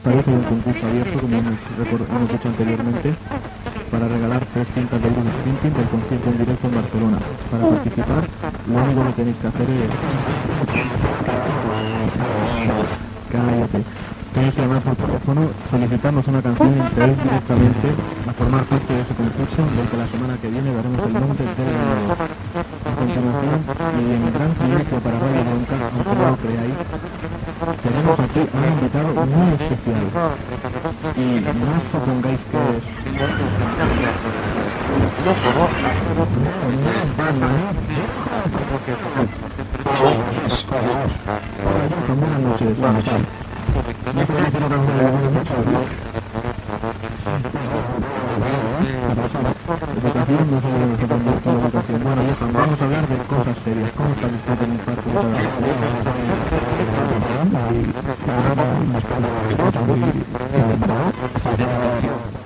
Banda FM
Qualitat de l'àudio deficiient